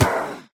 mob / witch / death3.ogg
should be correct audio levels.
death3.ogg